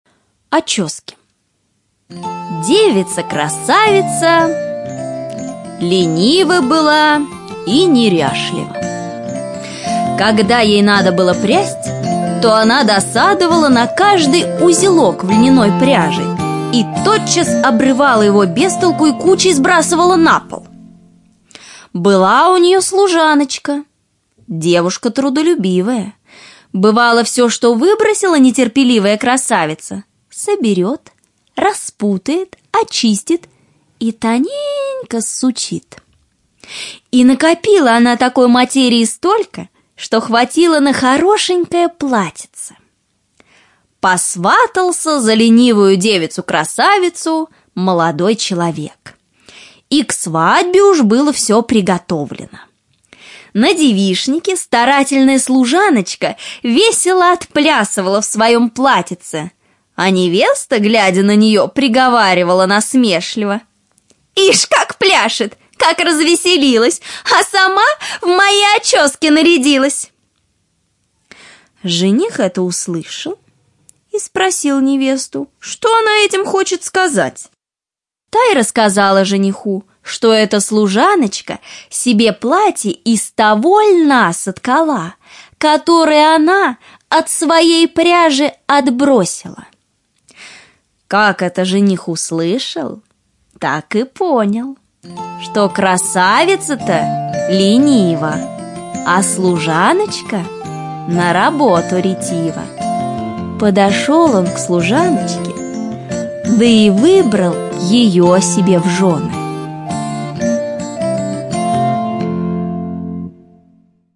Очески - аудиосказка братьев Гримм. Короткая сказка о ленивой красавице и трудолюбивой служанке…